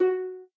harp.ogg